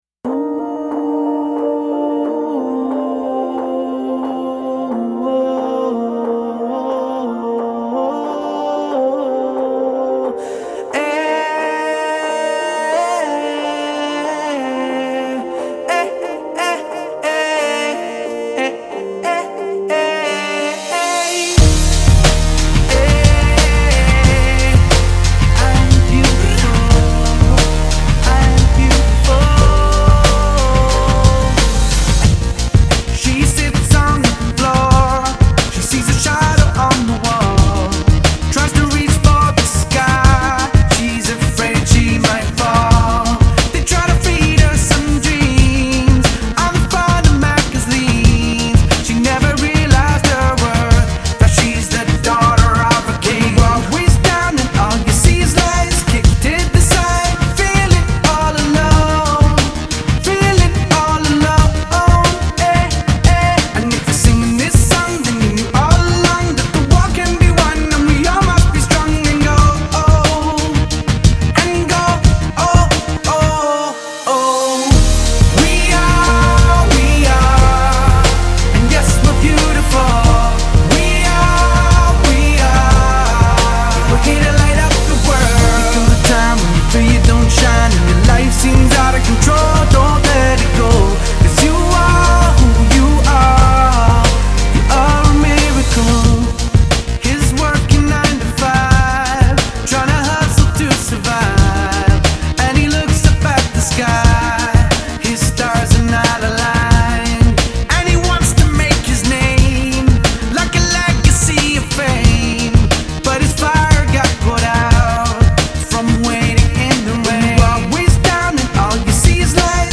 very positive, very upbeat.
Very uplifting and upbeat!